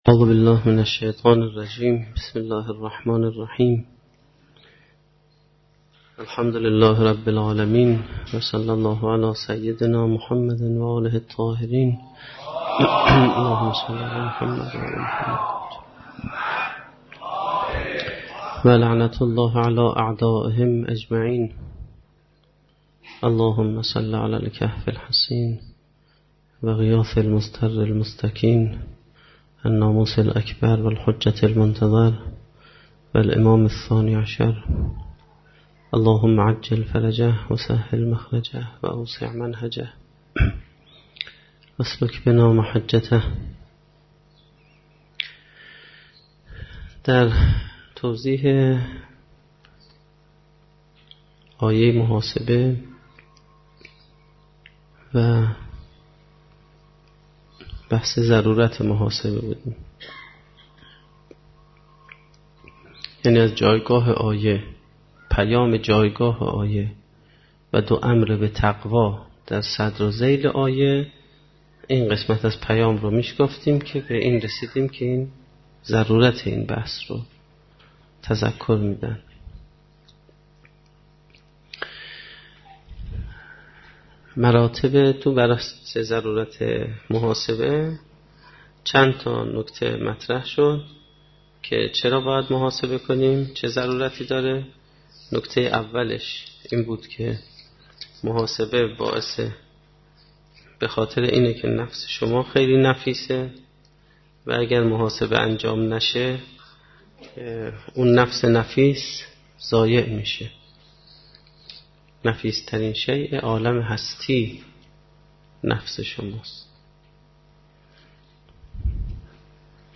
سخنرانی
در جلسه سیر و سلوک قرآنی